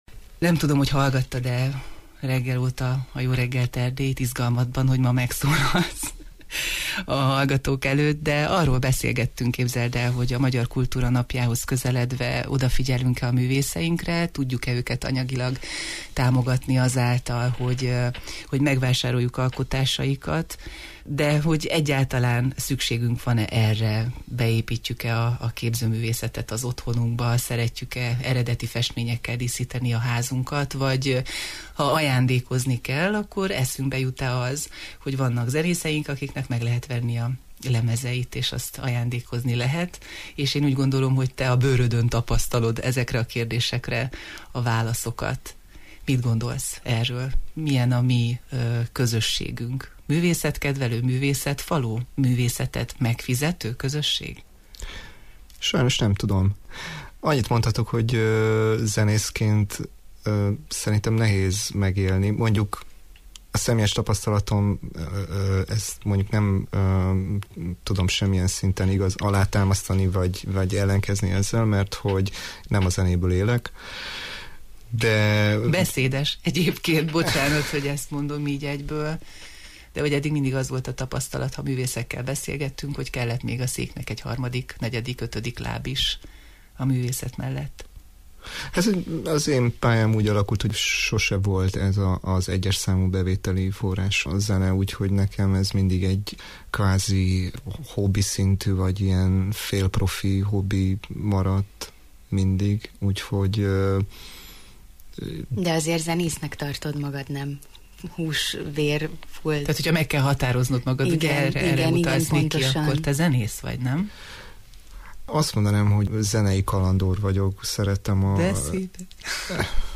Vele beszélgetünk arról, miként alakul tapasztalatában a szakma és a magánélet dinamikus tánca, színházi munkákról, felkérésekről és a szabadúszó zenész keresleti lehetőségeiről.